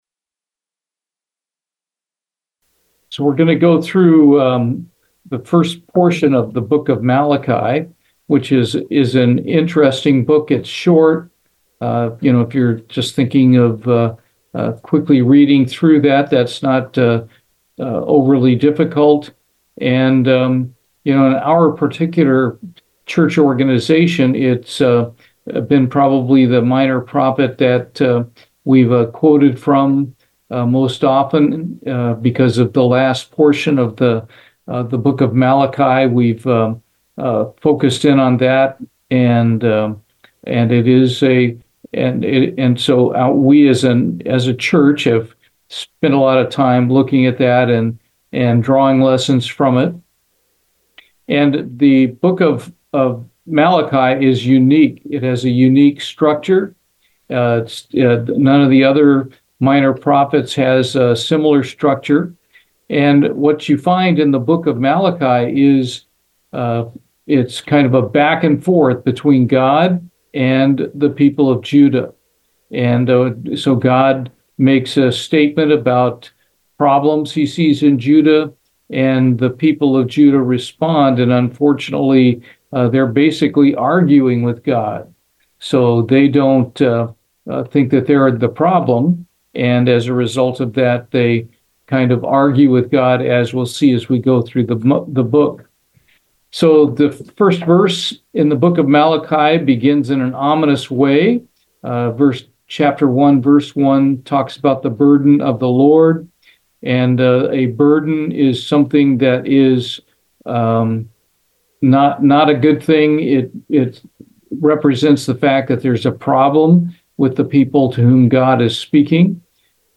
Bible Study, Malachi, Part 2
Given in Houston, TX